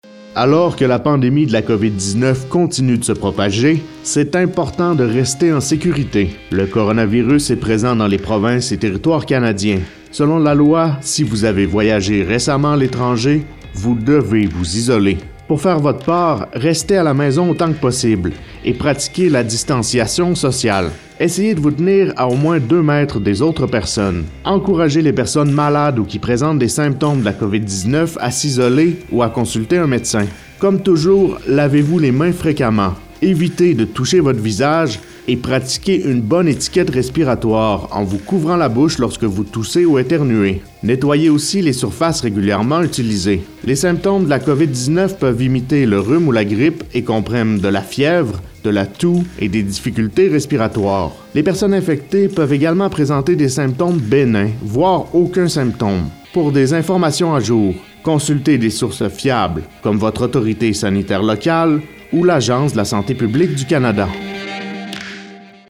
Type: PSA